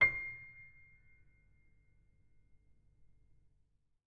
sampler example using salamander grand piano
C7.ogg